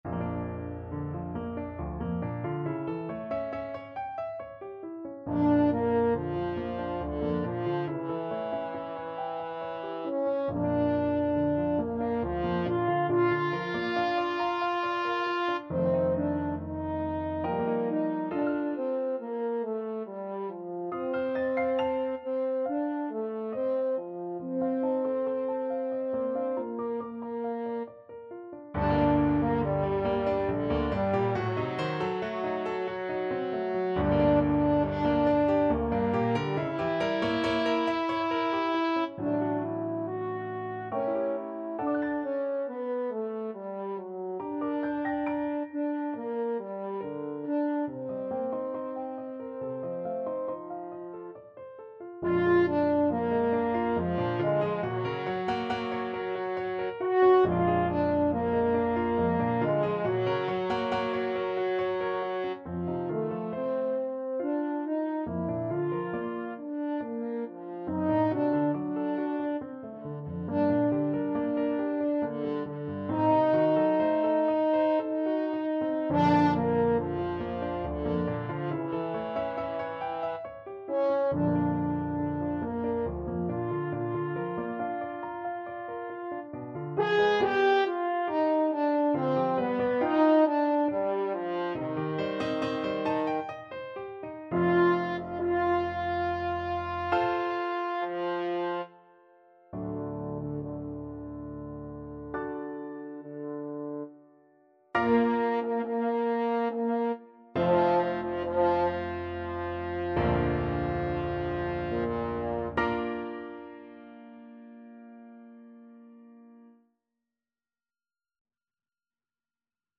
French Horn
Bb major (Sounding Pitch) F major (French Horn in F) (View more Bb major Music for French Horn )
3/4 (View more 3/4 Music)
~ = 69 Large, soutenu
Classical (View more Classical French Horn Music)